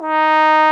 Index of /90_sSampleCDs/Roland LCDP12 Solo Brass/BRS_Trombone/BRS_Tenor Bone 3